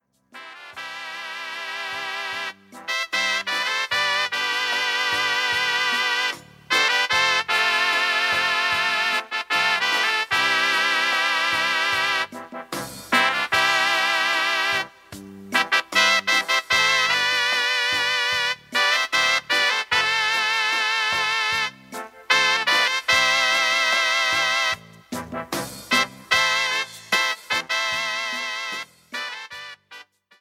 95-Banda-4.mp3